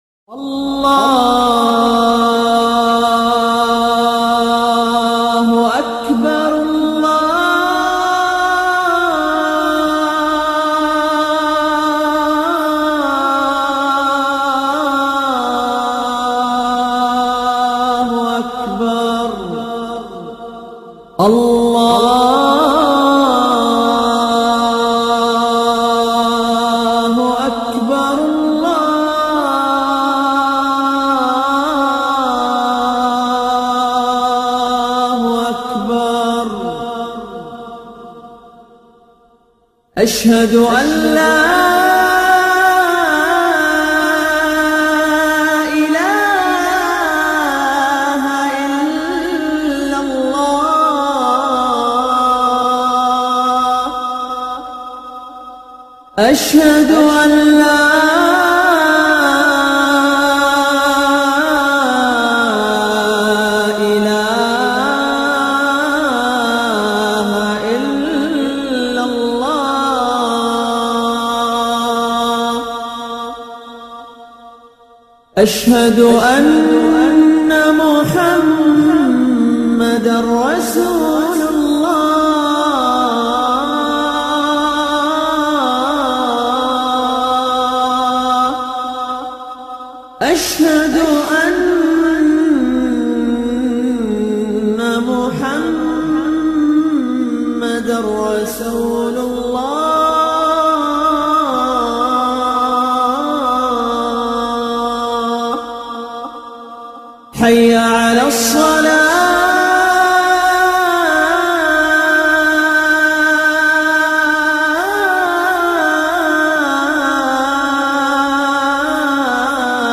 آذان